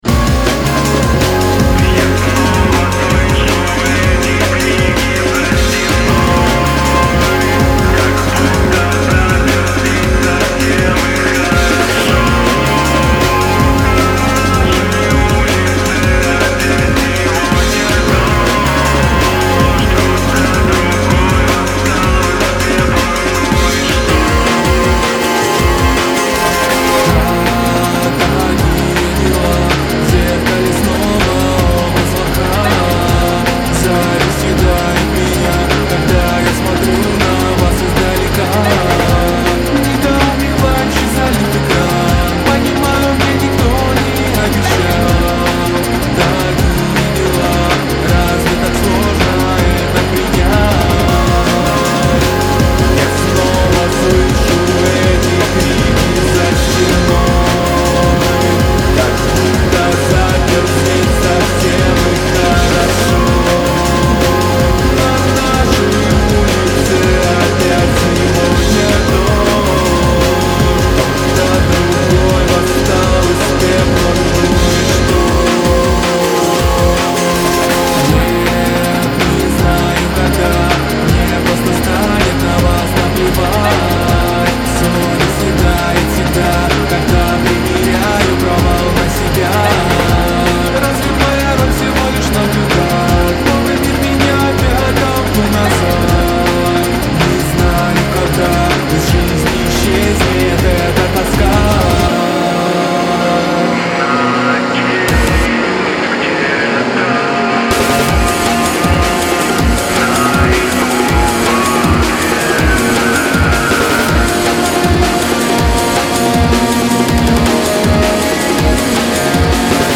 Категории: Русские песни, Рок.